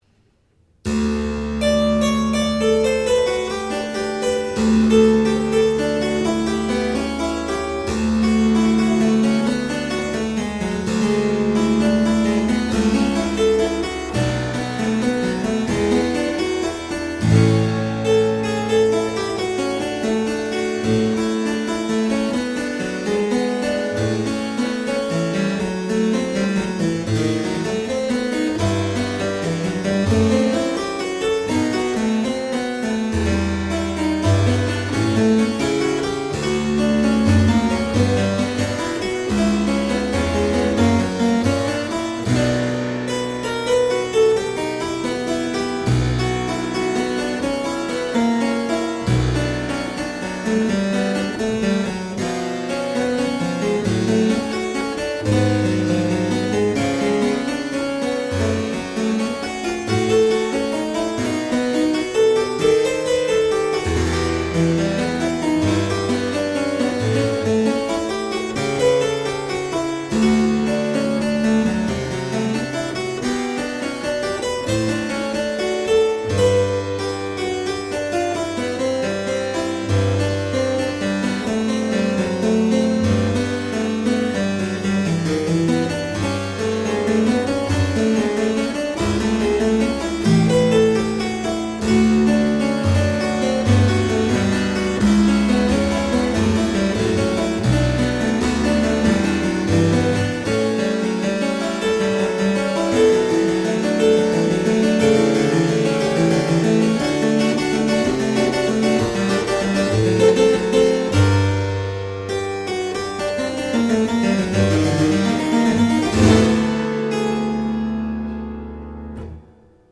three different beautiful harpsichords
Classical, Baroque, Instrumental, Harpsichord